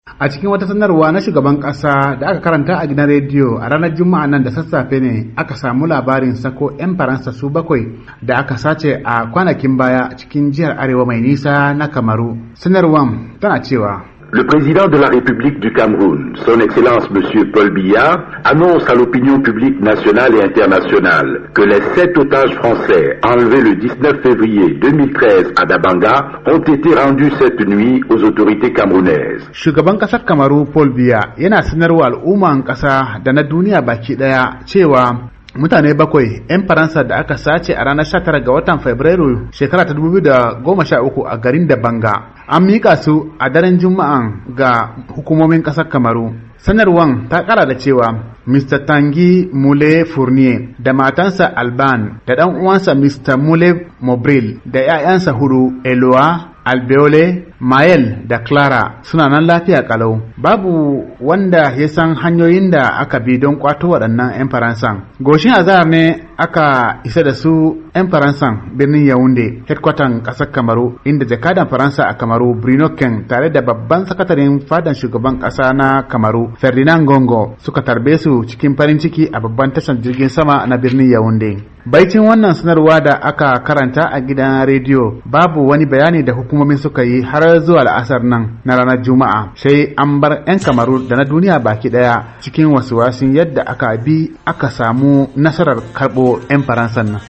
Rahoto Daga Kamaru Kan Sako Mutanen Da Aka Yi Garkuwa da Su - 1:37